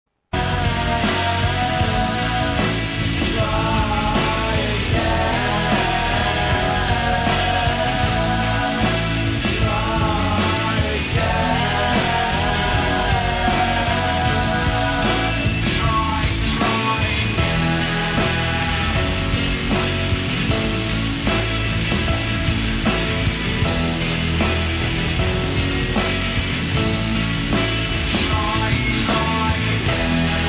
Indie Rock / Baggie / Brit Pop Classic Lp Reissue